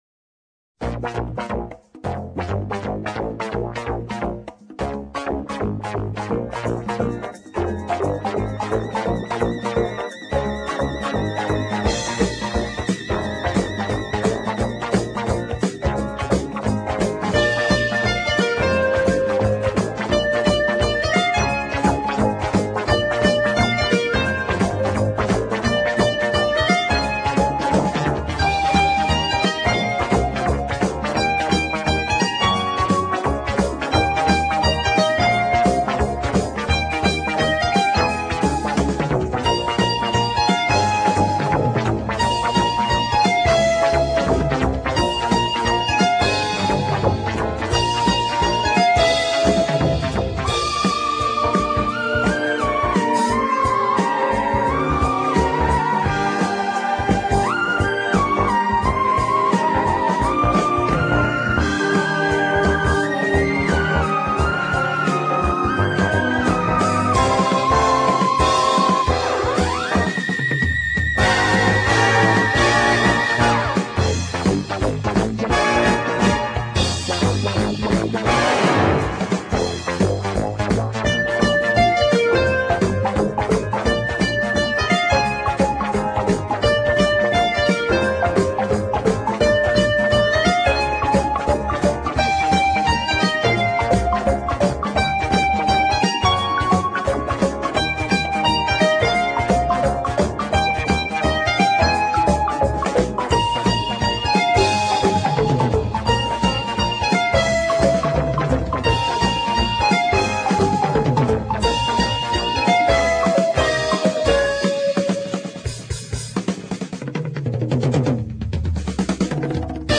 Générique :